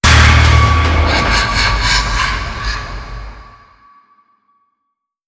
Jumpscare_01.wav